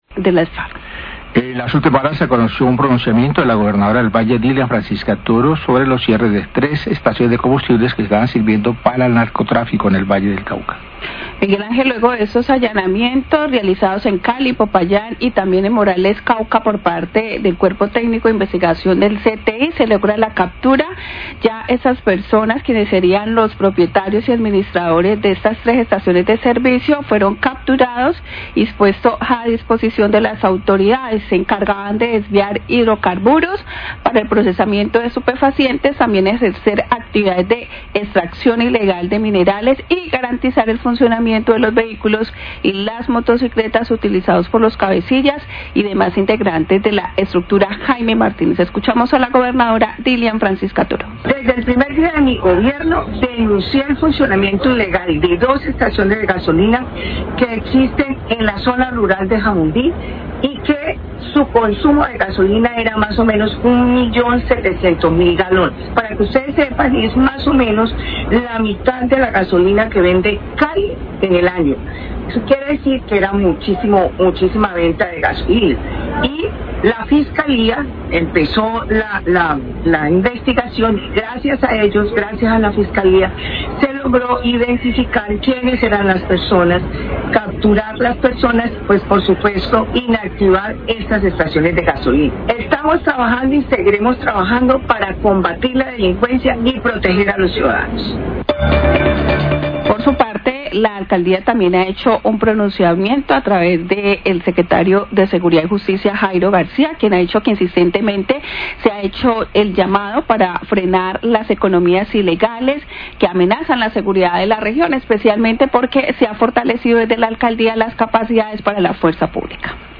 NOTICIERO RELÁMPAGO